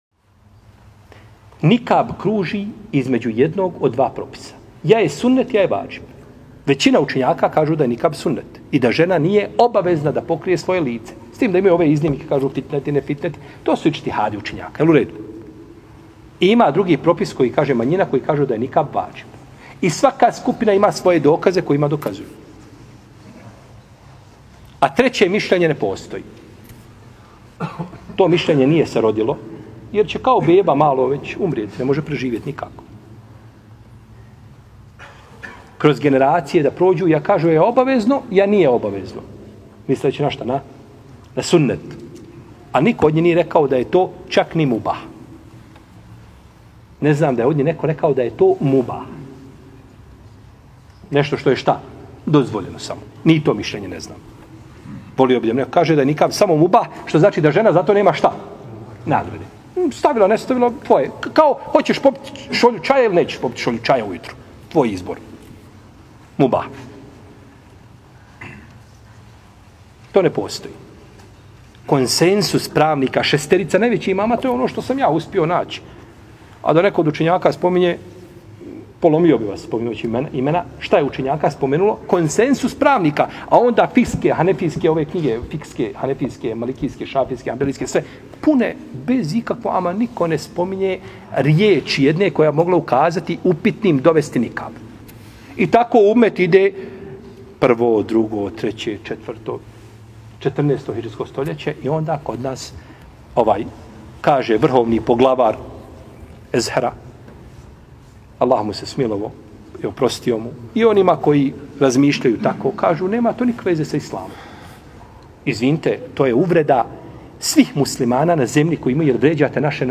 Audio mp3 odgovor